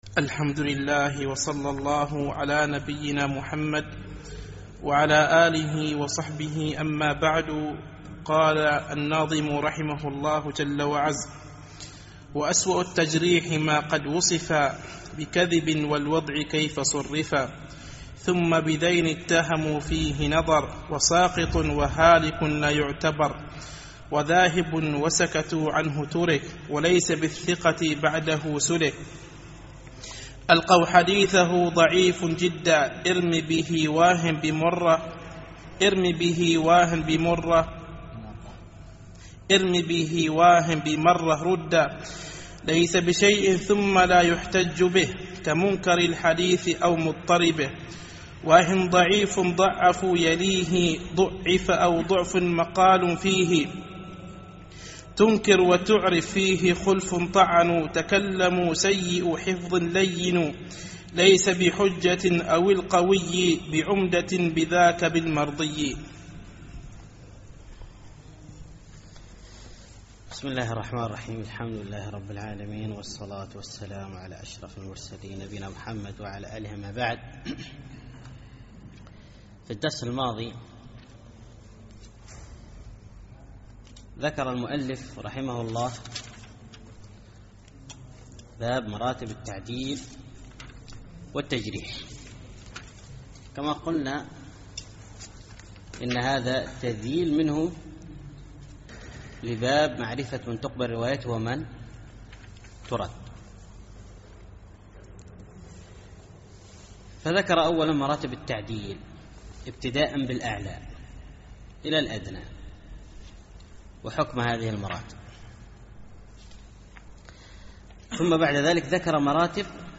الدرس السابع عشر